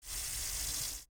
Hiss.mp3